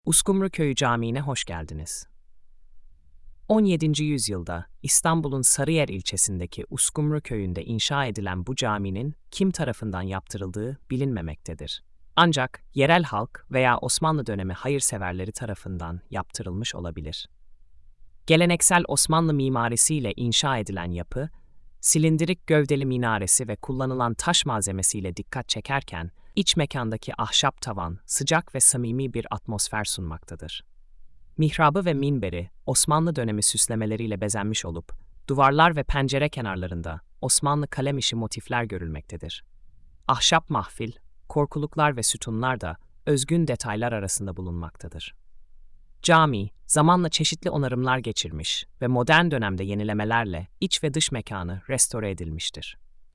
Sesli Anlatım